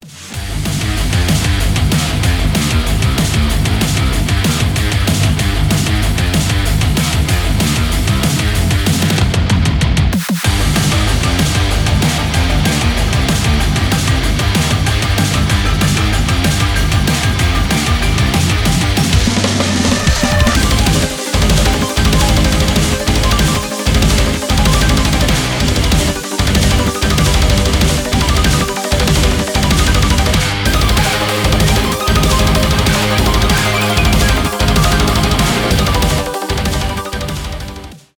рок , industrial metal
без слов , громкие
мощные